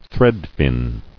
[thread·fin]